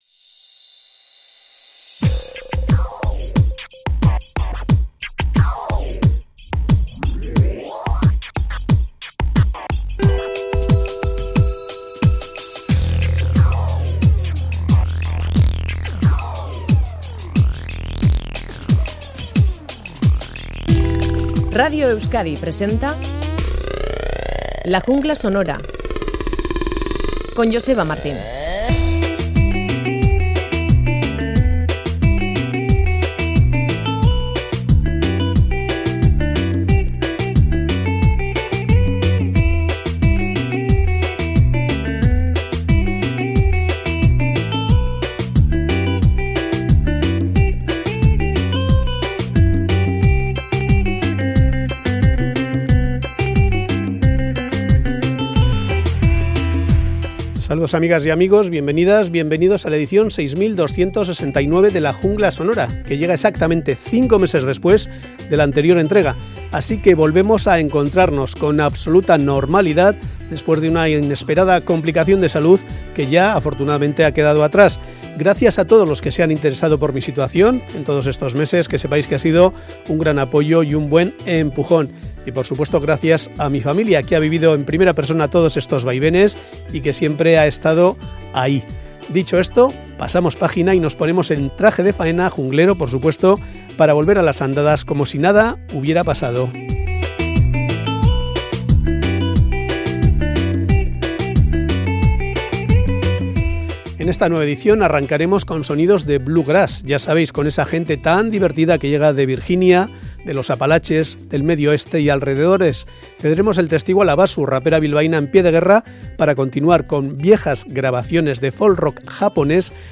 Audio: Folk-rock japonés de los primeros 70, novedades de bluegrass desde el Medio Oeste, La Basu sí te cree, el puente de Queensboro, más conocido como el puente de la calle 59...